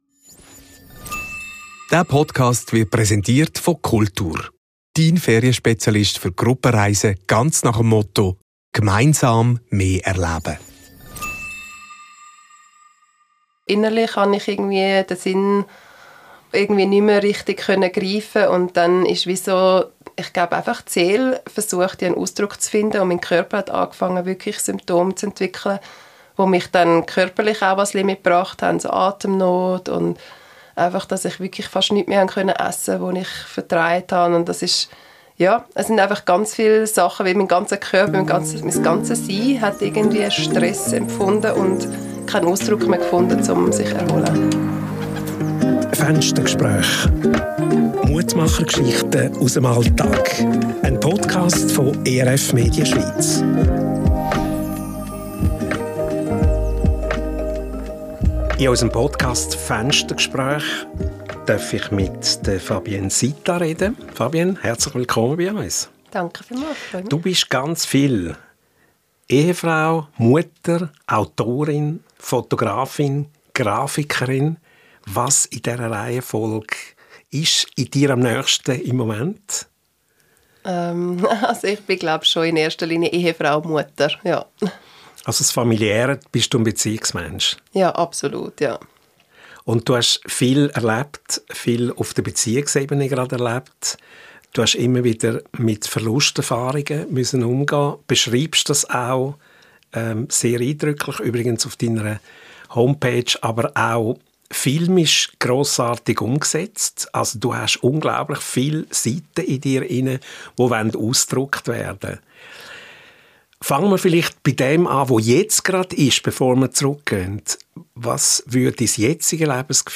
Gott finden durch Trauer und Schmerz ~ Fenstergespräch